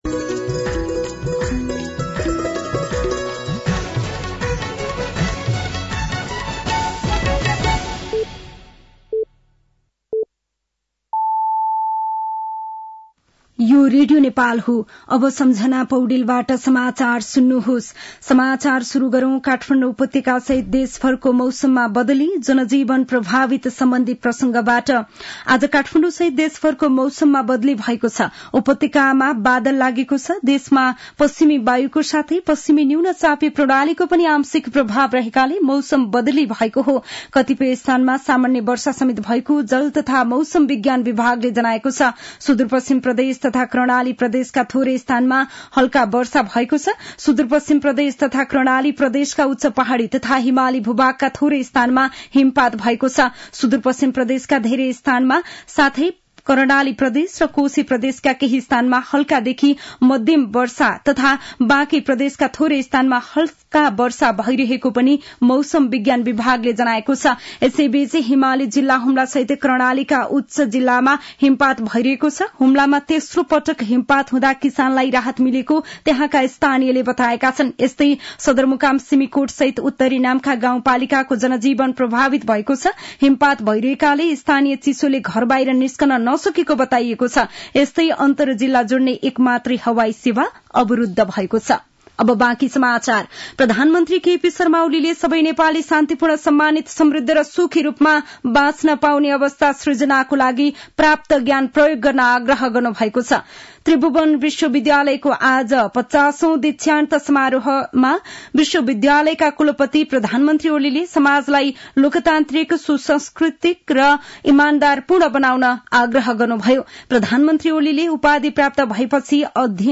साँझ ५ बजेको नेपाली समाचार : १४ पुष , २०८१
5-pm-news-9-13.mp3